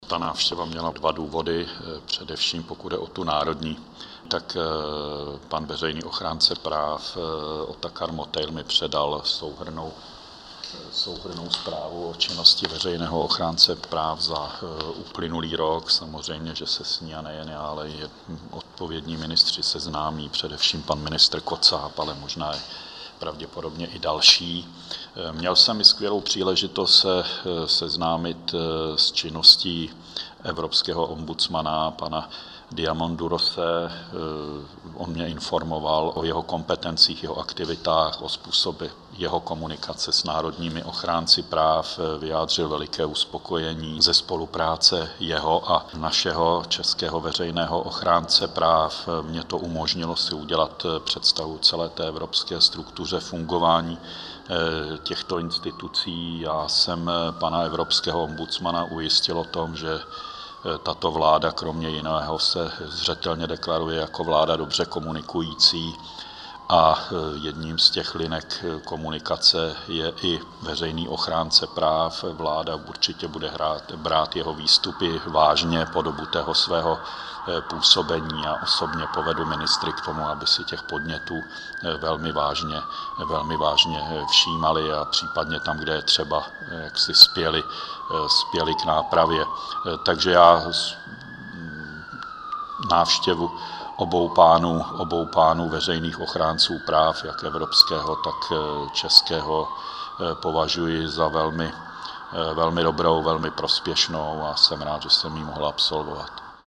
„Já jsem pana evropského ombudsmana ujistil o tom, že tato vláda kromě jiného se zřetelně deklaruje jako vláda dobře komunikující a jednou z těch linek komunikace je i veřejný ochránce práv,“ řekl premiér Fischer.